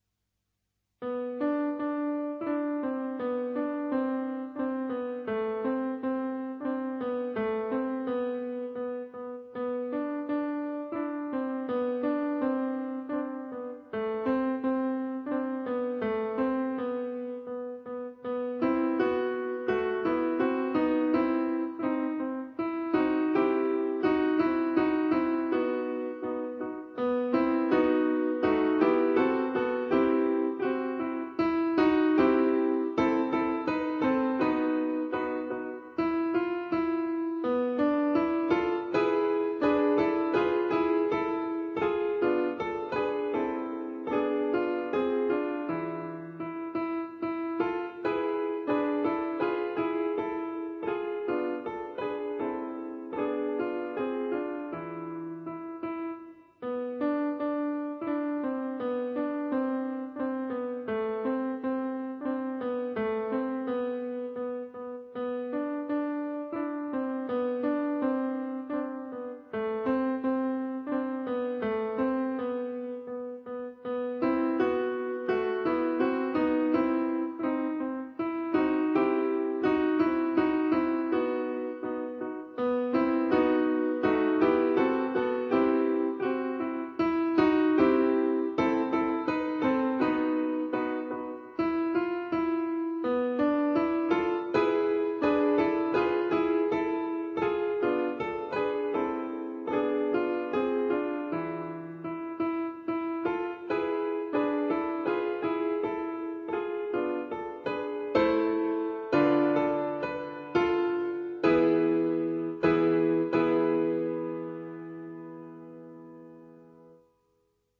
ADATTAMENTI PER PIANO